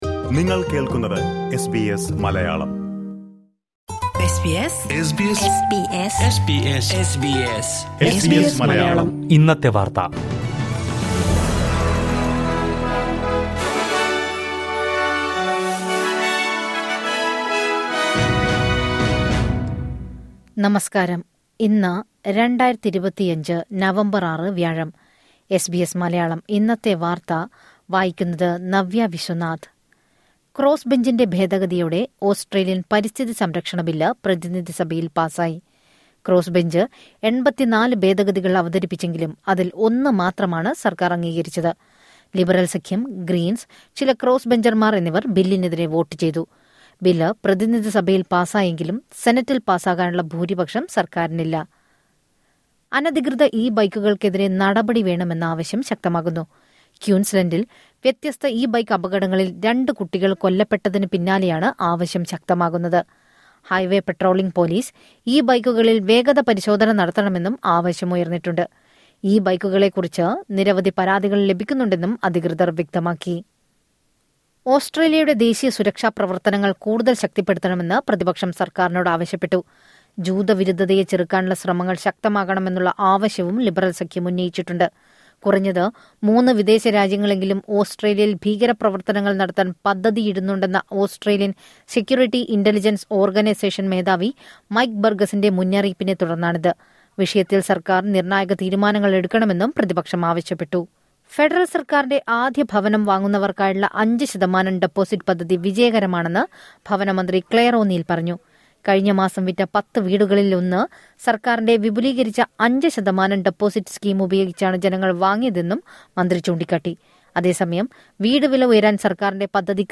2025 നവംബർ ആറിലെ ഓസ്ട്രേലിയയിലെ ഏറ്റവും പ്രധാന വാർത്തകൾ കേൾക്കാം...